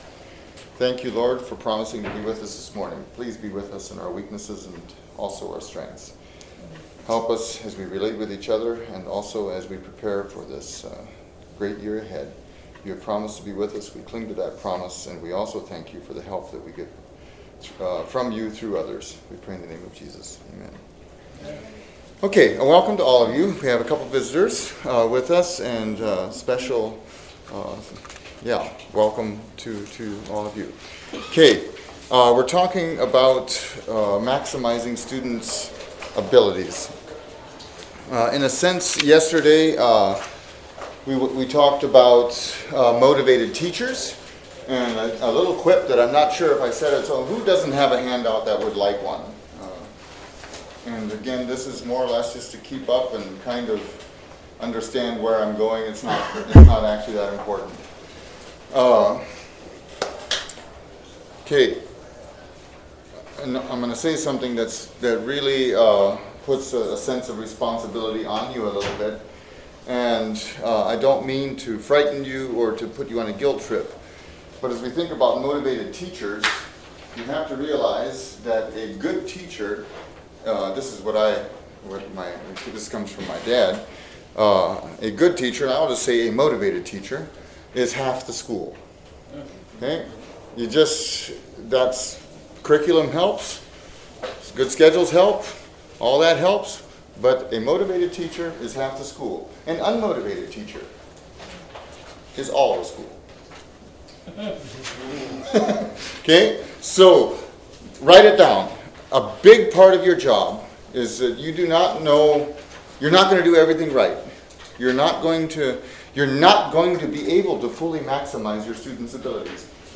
Midwest Teachers Week 2025 Recordings